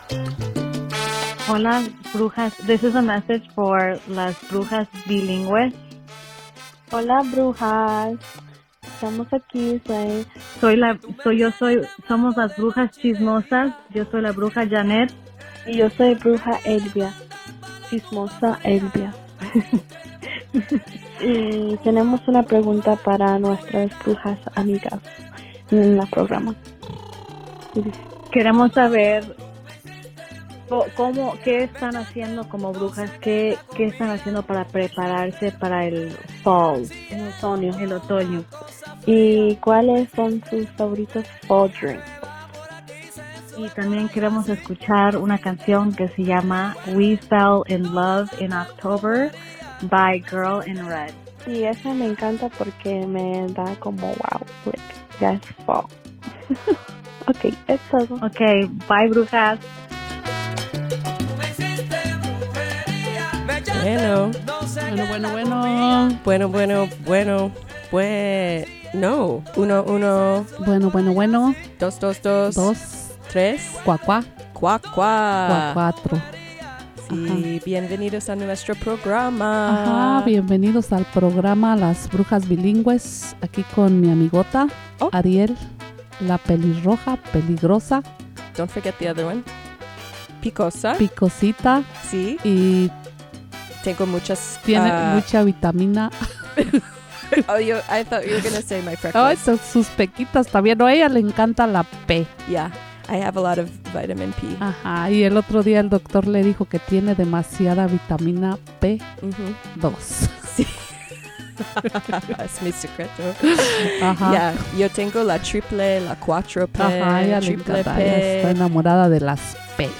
It's October and the veil is thin. This week, Las Brujas Bilingues are getting ready for fall with special potions and spooky music.